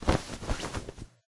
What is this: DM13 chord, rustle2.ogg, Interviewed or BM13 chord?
rustle2.ogg